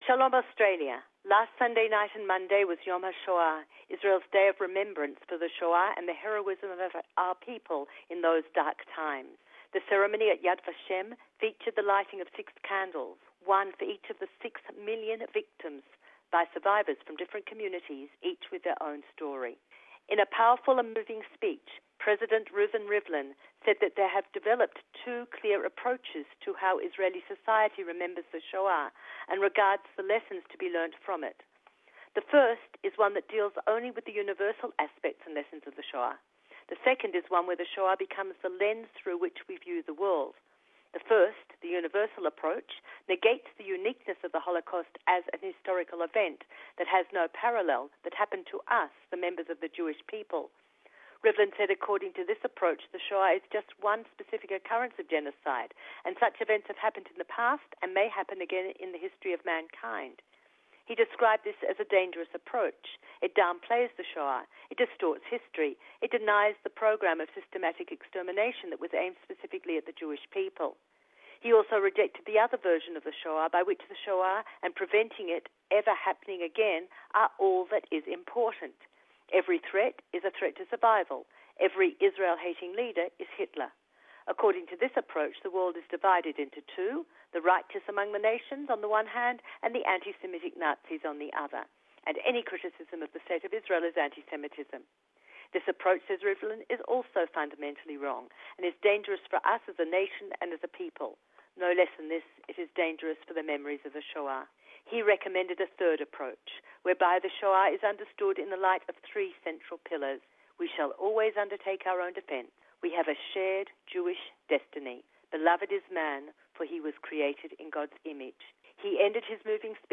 current affairs report